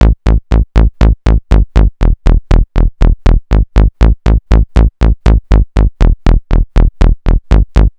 TSNRG2 Bassline 005.wav